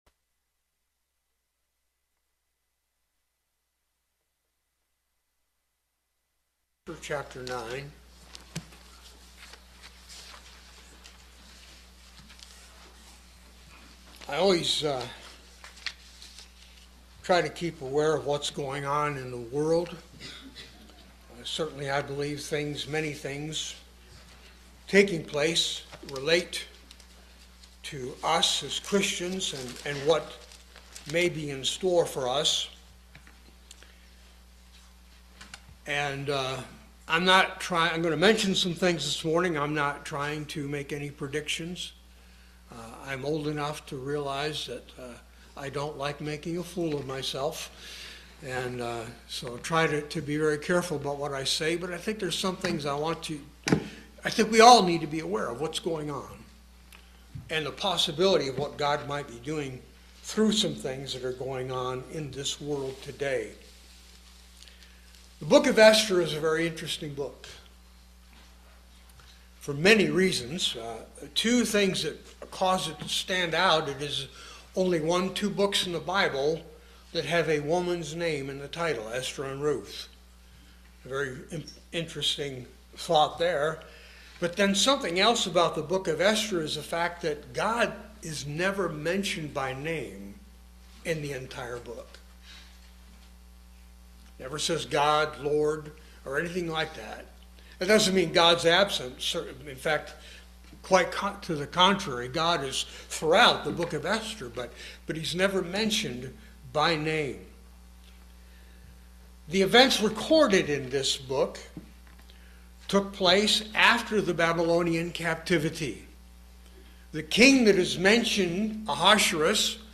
Passage: Romans 14:7 Service Type: Sunday Worship